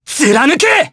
Theo-Vox_Skill6_jp.wav